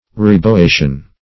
Meaning of reboation. reboation synonyms, pronunciation, spelling and more from Free Dictionary.